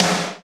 Index of /90_sSampleCDs/Roland - Rhythm Section/KIT_Drum Kits 6/KIT_Combo Kit
SNR COMBO03L.wav